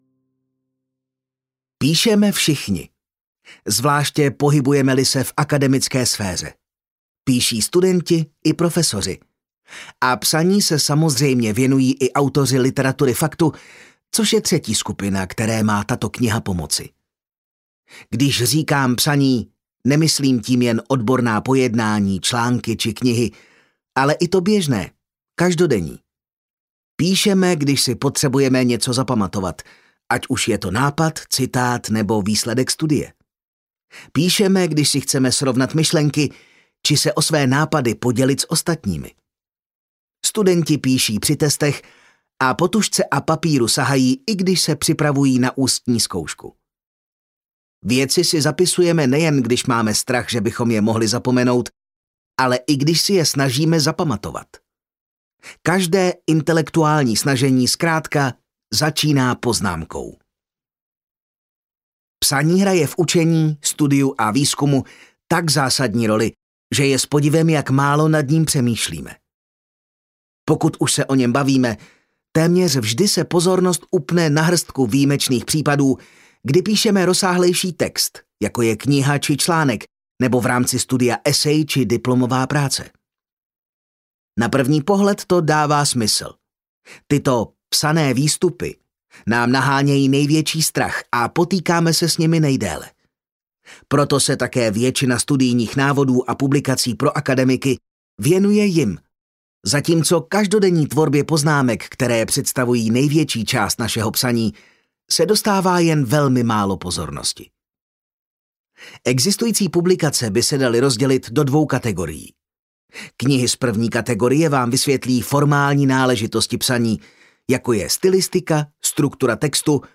Audiokniha Jak si dělat chytré poznámky - Sönke Ahrens